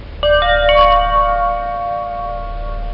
Chime Sound Effect
Download a high-quality chime sound effect.
chime-2.mp3